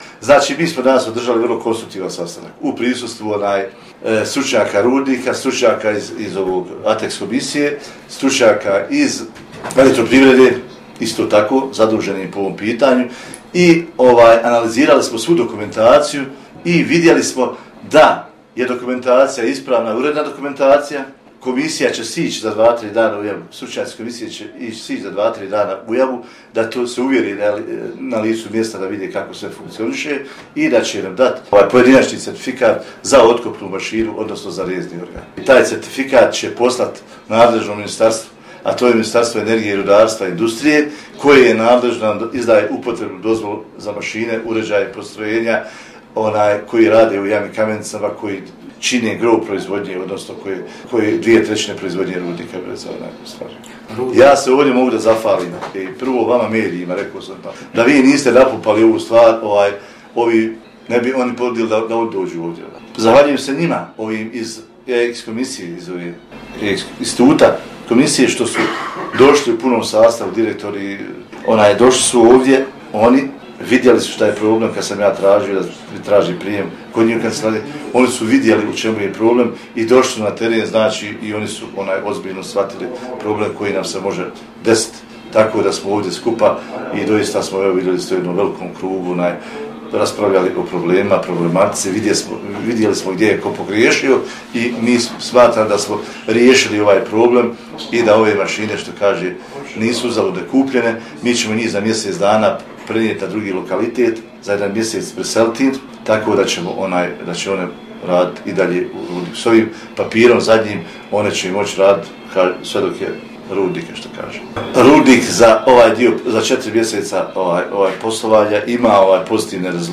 Poslije sastanka održana je press konferencija na kojoj su se obratili :